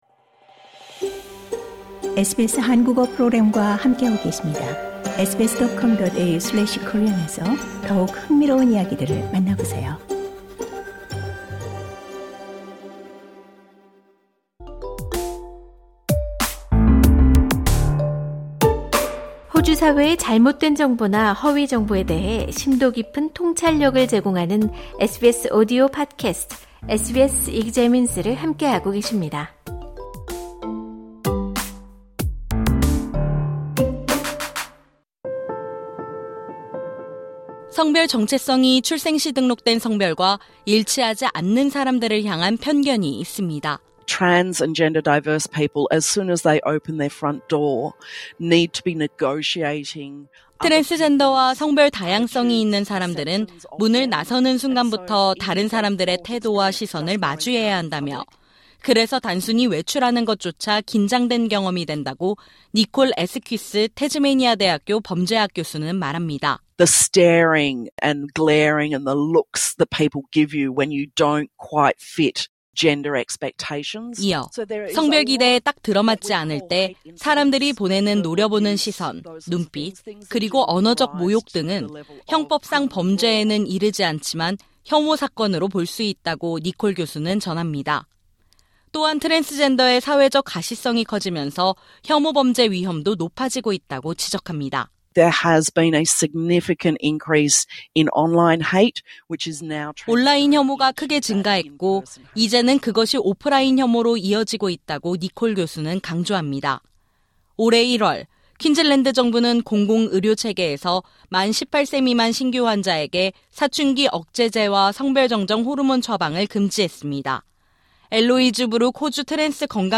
이번 증오 이해하기(Understanding Hate) 에피소드에서는 호주에서 트랜스포비아의 영향을 살펴봅니다. 상단의 오디오를 재생하시면 뉴스를 들으실 수 있습니다.